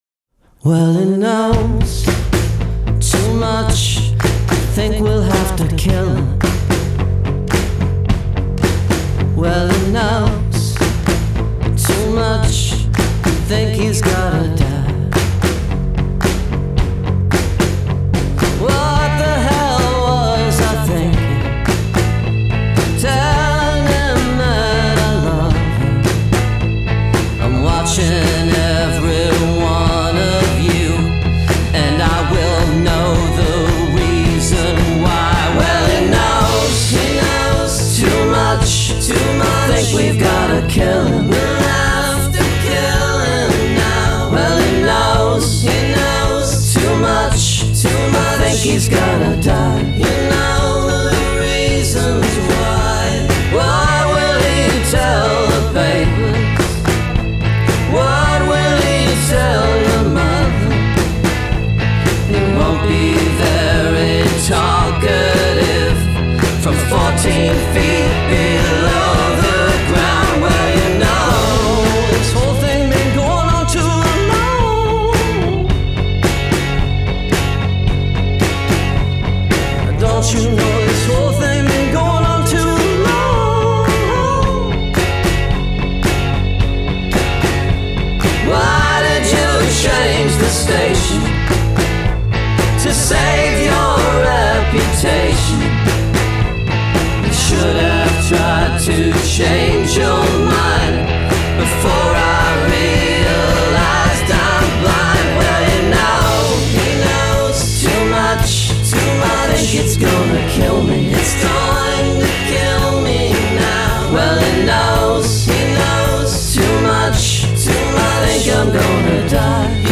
from its breathy opening vocal line
both the lead and backing – nail all the hooks